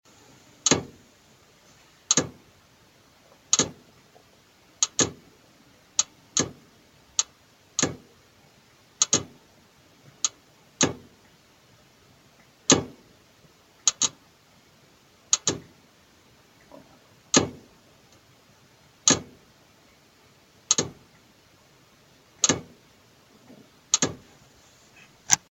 Kdo mi pom��e s bouch�n�m v radi�toru topen� v Plzni?
Jedn� se o 7-mi patrovou bytovku a jsem ve �tvrt�m pat�e.